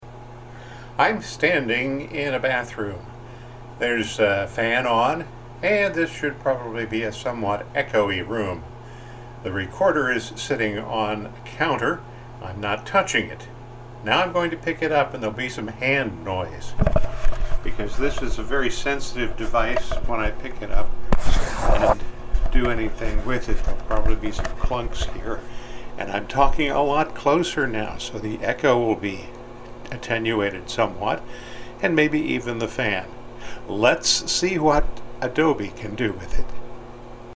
Here’s an example of a recording made with a fan running in the background.
Noisy.mp3